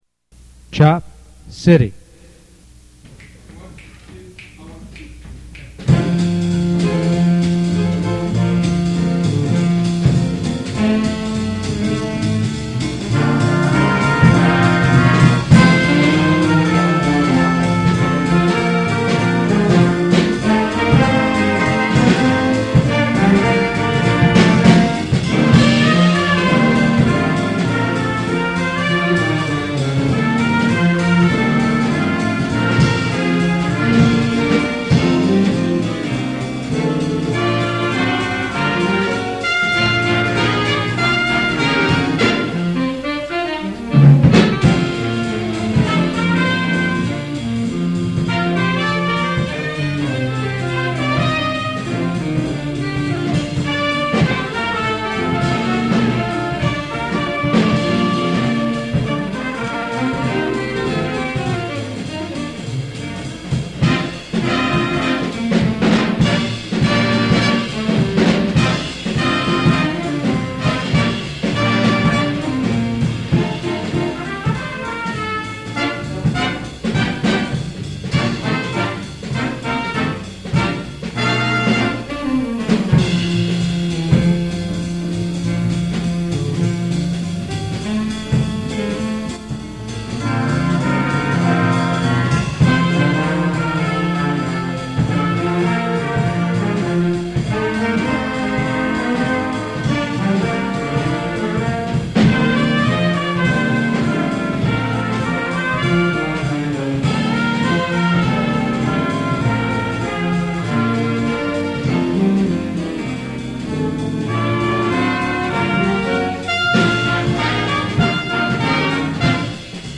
Big Band Jazz Compositions for Schools/Colleges...
" Big Band Jazz Arrangement  2:60  JHS-HS